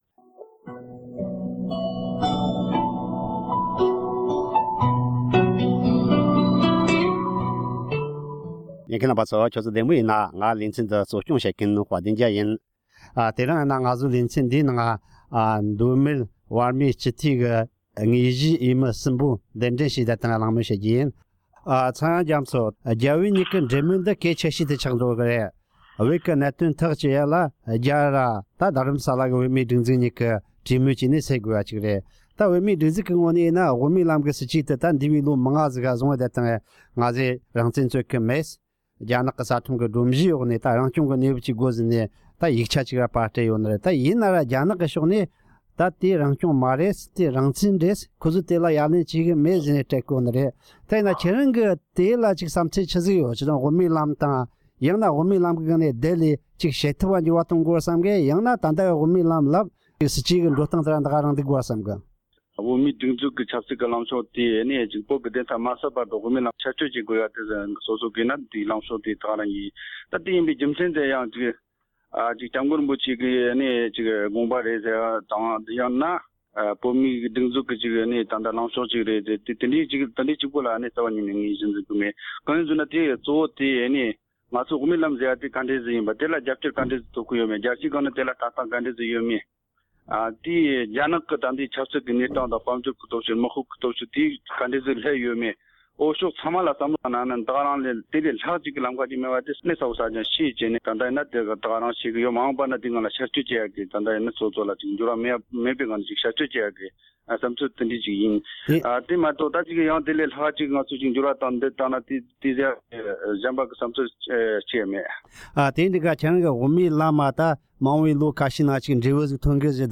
བོད་རྒྱའི་འབྲེལ་མོལ་དང་། རྒྱ་གར་དང་བལ་ཡུལ་ནས་བོད་མི་ཕྱི་རྒྱལ་ལ་གཏོང་བའི་སྲིད་ཇུས་ཐད་མདོ་སྨད་སྤྱི་འཐུས་ཀྱི་འོས་མི་ཁག་དང་གླེང་བ།